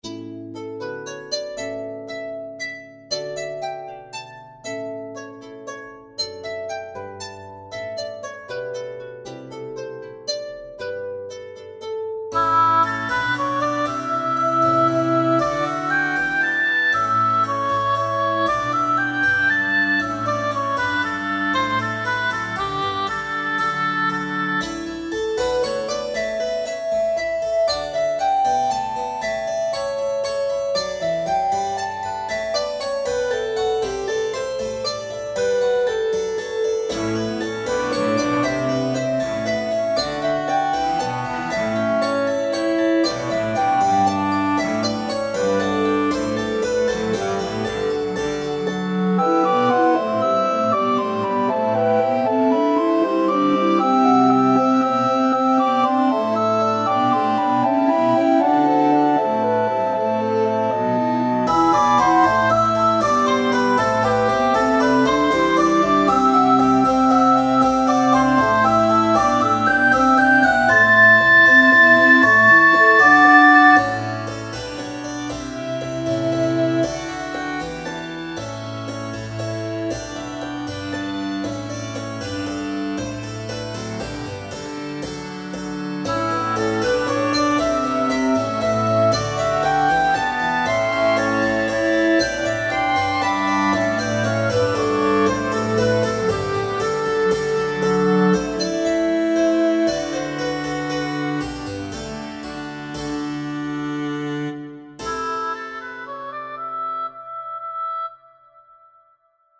Das erste Stück greift erstmals nach längerer Zeit wieder auf ein Virginal zurück, beim zweiten handelt es sich um eine Komposition für Harfe, Gamben, Cembalo und Renaissanceflöten.